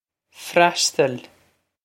Freastail Fras-tahl
Pronunciation for how to say
This is an approximate phonetic pronunciation of the phrase.